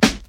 • Classic Natural Hip-Hop Snare D# Key 21.wav
Royality free steel snare drum tuned to the D# note. Loudest frequency: 1881Hz
classic-natural-hip-hop-snare-d-sharp-key-21-Pm7.wav